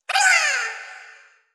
Звуки ниндзя
Ниндзя вопит кия